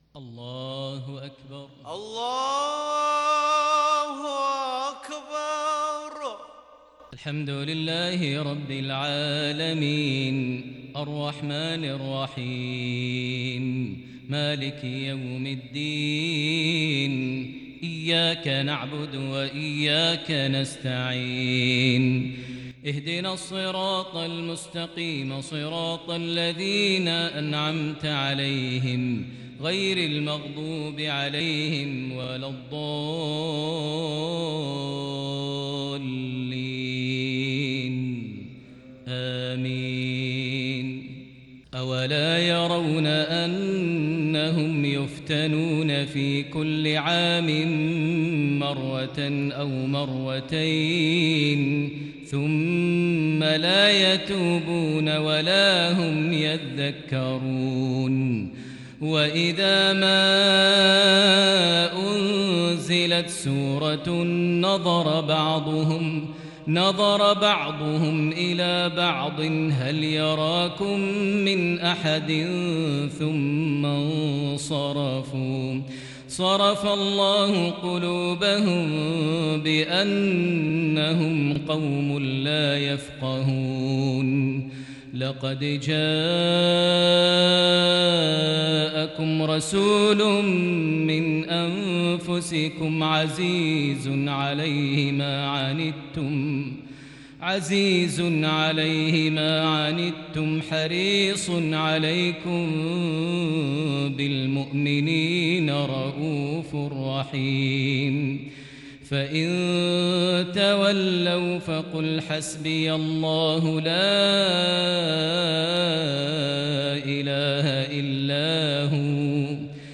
صلاة المغرب للشيخ ماهر المعيقلي 21 صفر 1442 هـ
تِلَاوَات الْحَرَمَيْن .